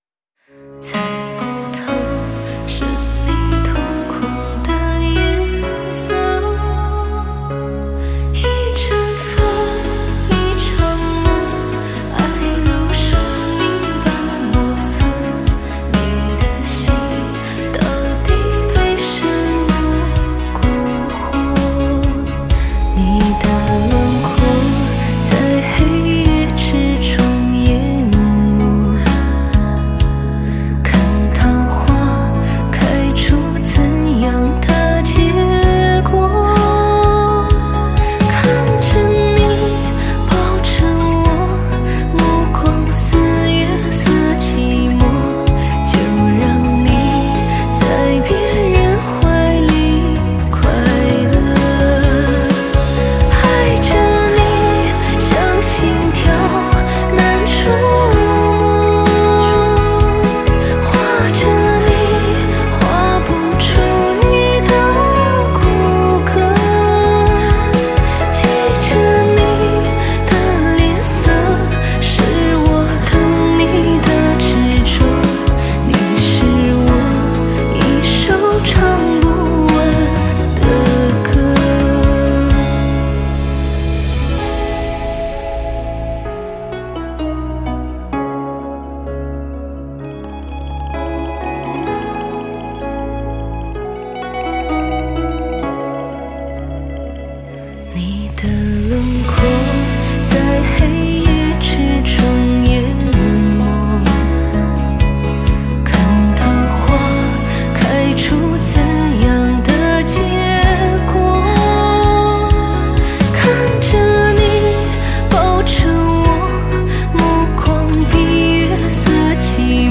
抒情的小美女声音